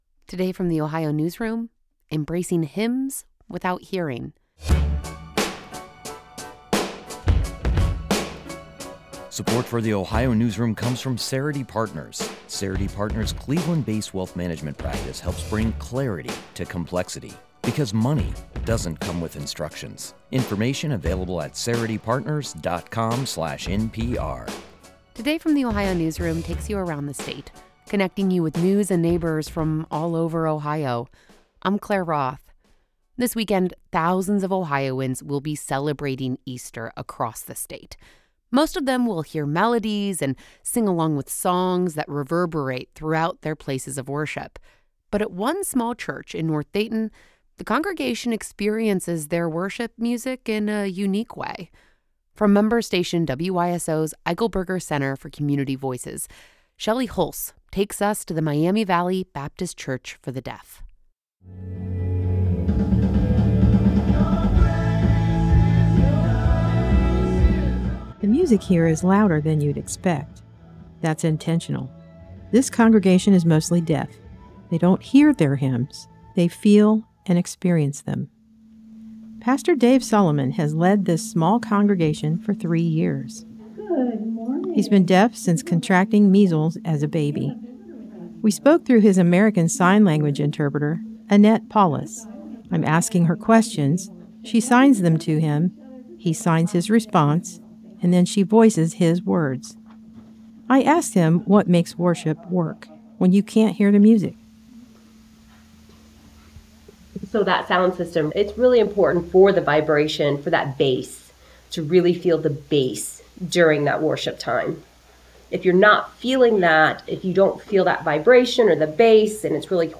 The music there is louder than you'd expect. Concert-level in an intimate setting.
Bass thumping, hands moving.